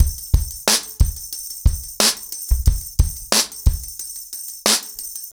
ROOTS-90BPM.7.wav